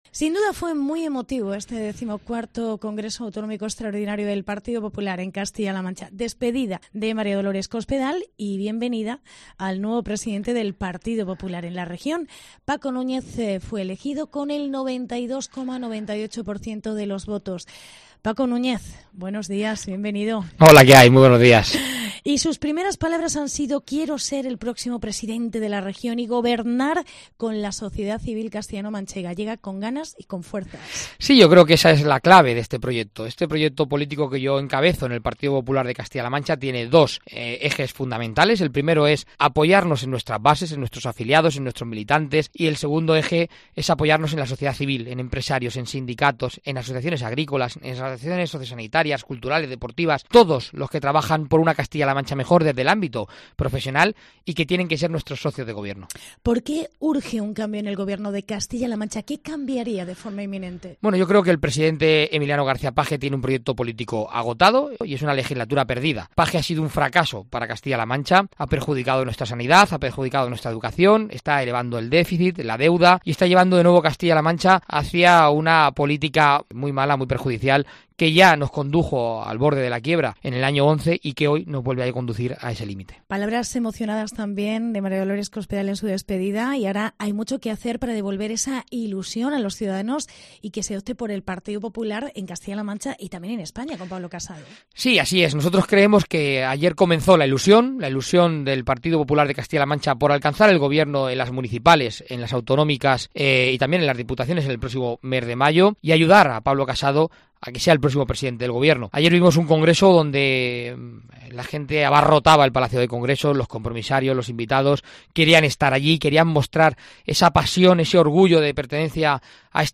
Entrevista con el nuevo presidente del PP en CLM: Paco Núñez